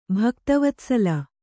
♪ bhakta vatsala